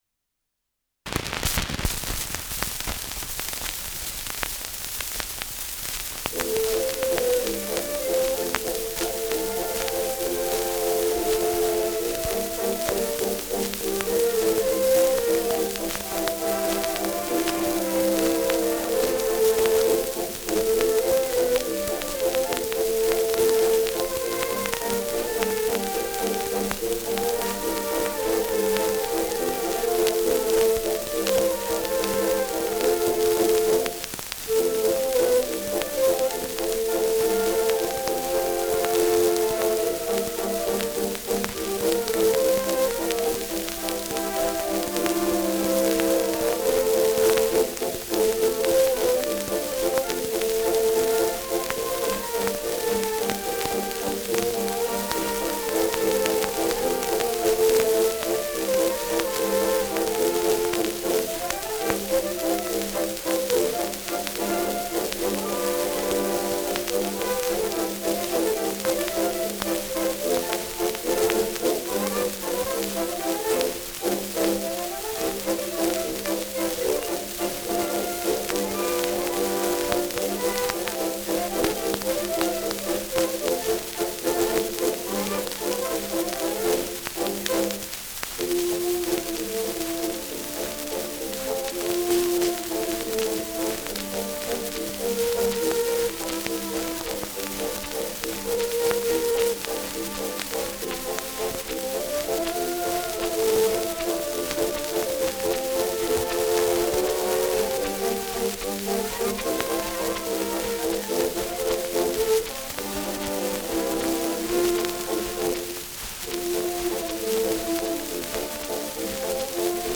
Schellackplatte
Stark abgespielt : Erhöhtes Grundrauschen : Häufiges Knacken : Nadelgeräusch
[unbekanntes Ensemble] (Interpretation)